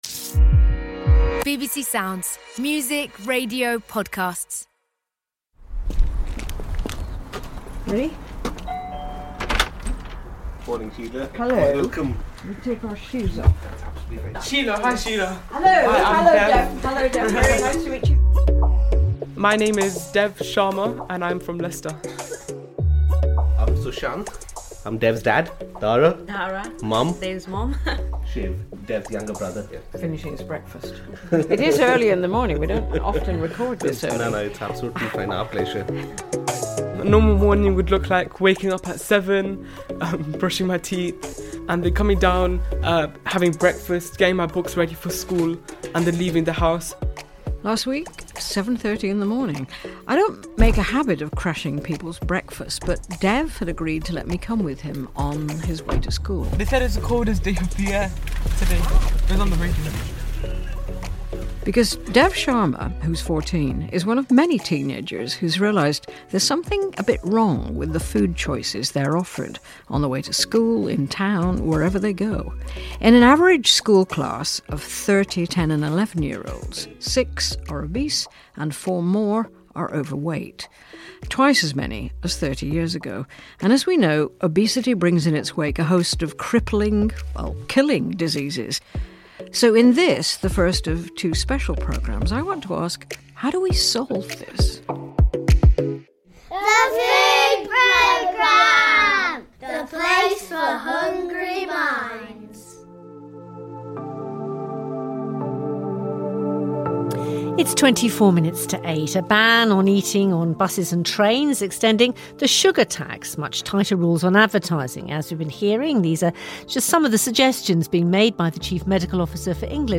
Sheila Dillon asks the help of children and young people, teachers and academics, to understand how we might cut child obesity related health problems in the UK.